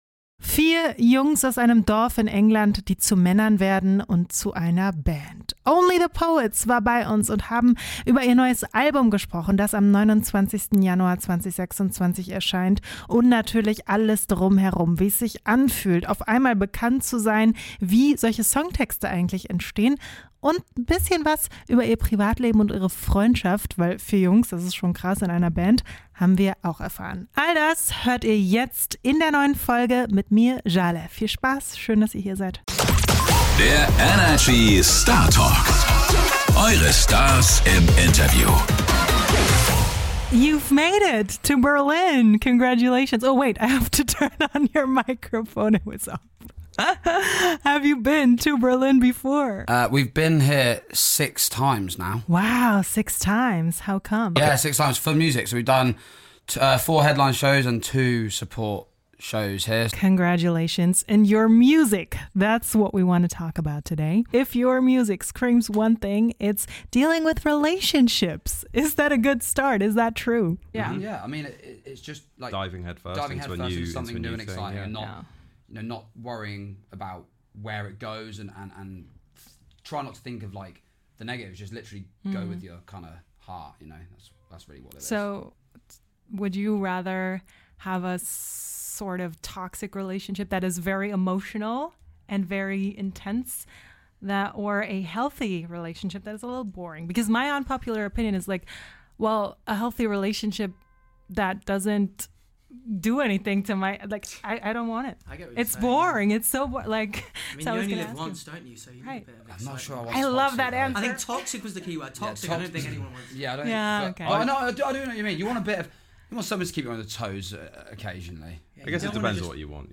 Die Band spricht über ihr bevorstehendes Album, den kreativen Prozess hinter ihrer Musik und darüber, wie Songs bei ihnen entstehen. Außerdem verraten OTP, was sie aktuell inspiriert und was Fans in nächster Zeit von ihnen erwarten können.